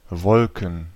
Ääntäminen
Ääntäminen Tuntematon aksentti: IPA: /ˈvɔlkn/ Haettu sana löytyi näillä lähdekielillä: saksa Käännöksiä ei löytynyt valitulle kohdekielelle. Wolken on sanan Wolke monikko.